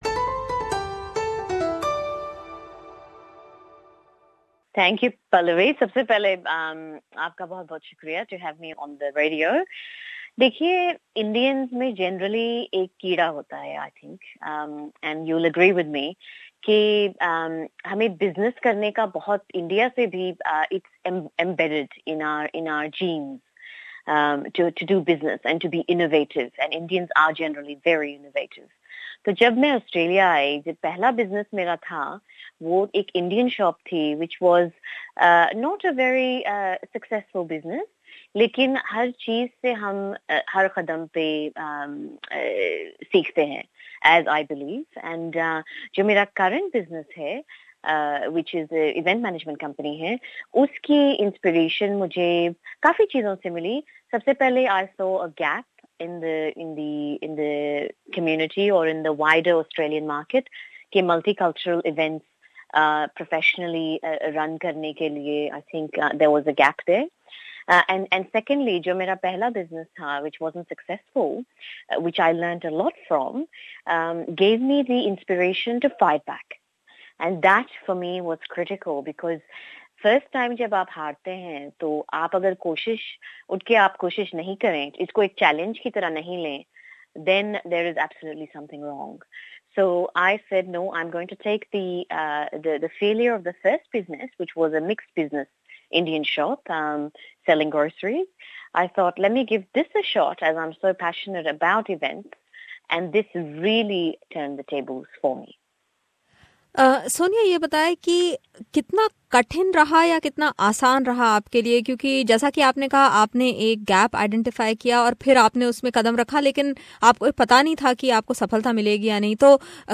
As part of our small business series today we present this free flowing chat